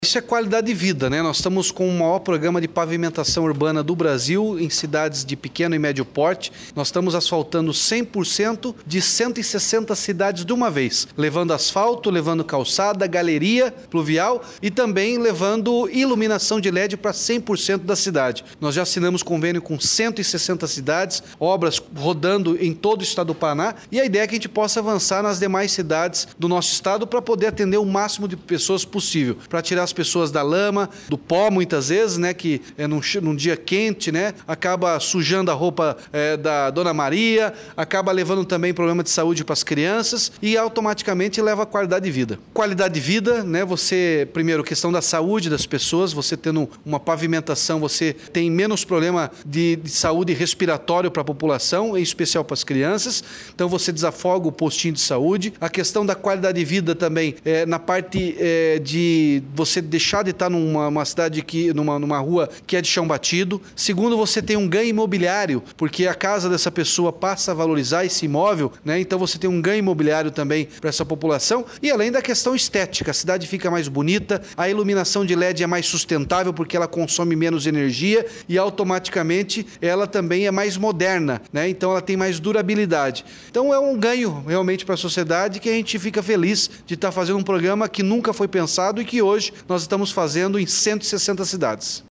Sonora do governador Ratinho Junior sobre a liberação de mais R$ 53,8 milhões no Asfalto Novo, Vida Nova